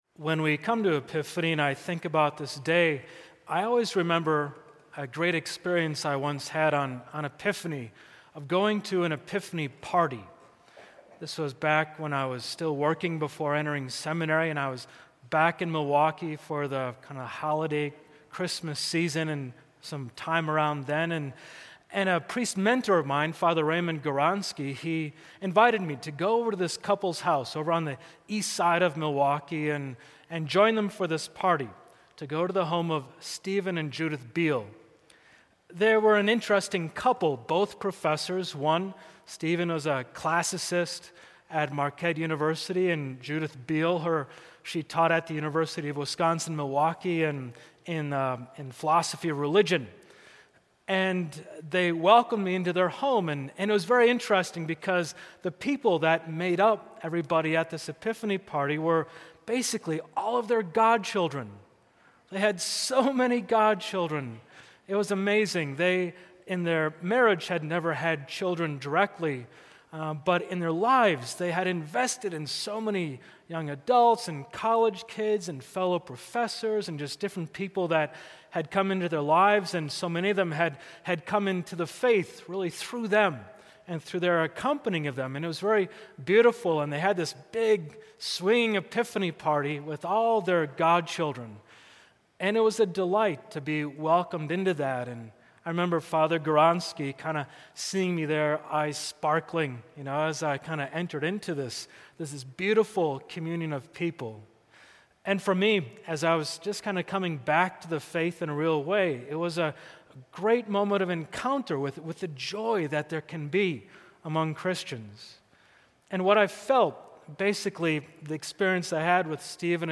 Epiphany Homily
epiphany-homily.mp3